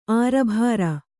♪ ārabhāra